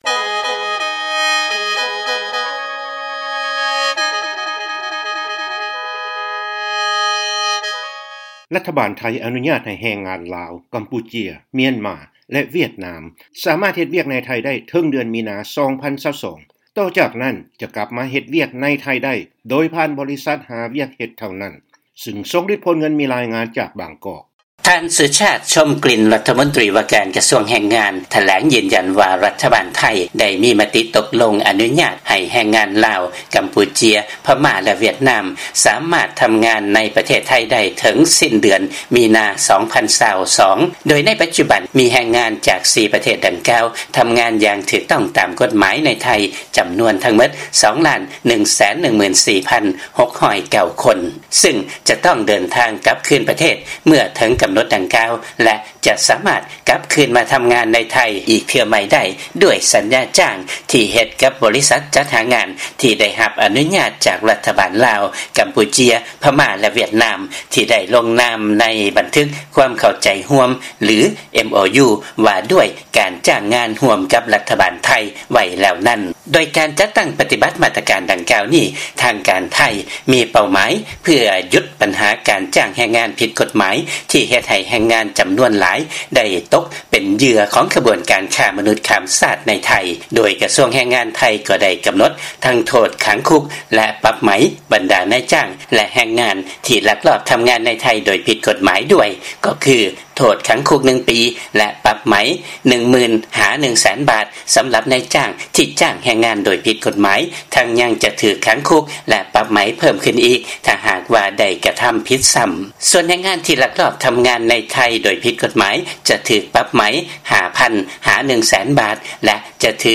ຟັງລາຍງານ ລັດຖະບານ ໄທ ອະນຸຍາດໃຫ້ແຮງງານ ລາວ, ກຳປູເຈຍ, ພະມ້າ ແລະ ຫວຽດນາມ ສາມາດເຮັດວຽກໃນ ໄທ ໄດ້ເຖິງເດືອນມີນາ 2022